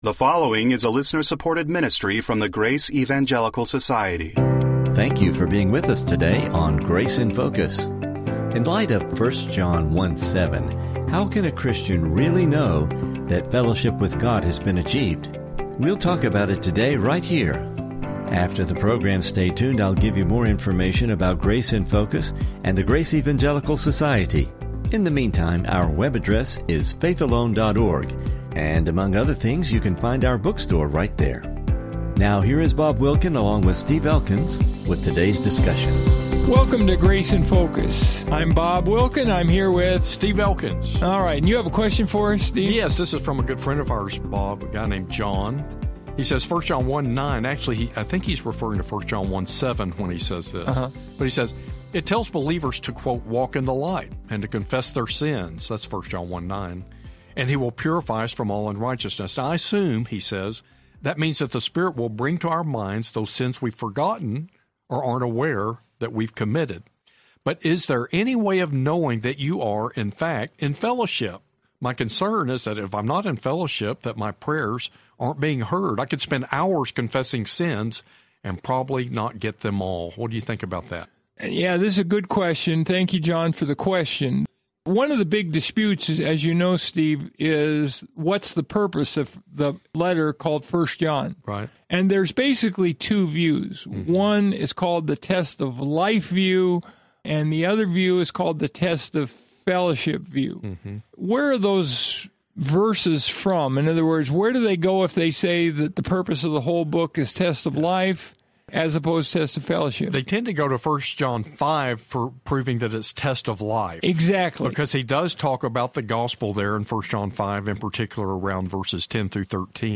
We hope you enjoy the discussion.